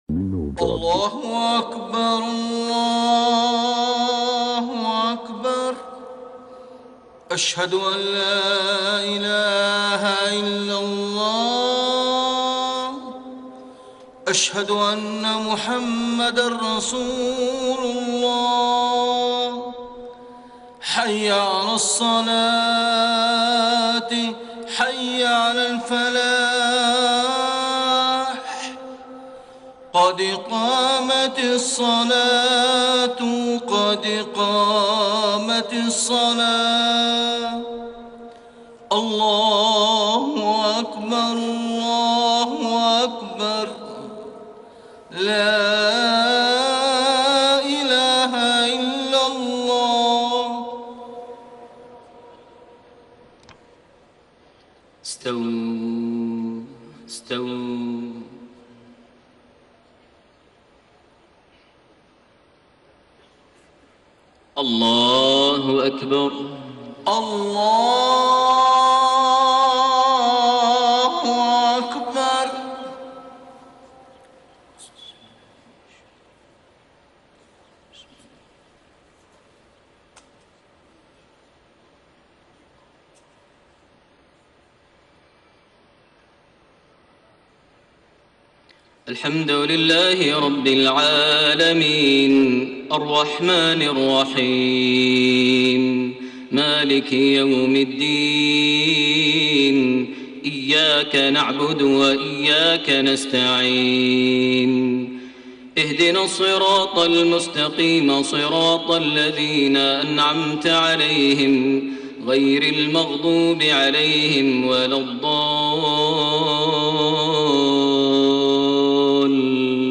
صلاة الفجر 18 رجب 1432هـ | من سورة آل عمران 121-136 > 1432 هـ > الفروض - تلاوات ماهر المعيقلي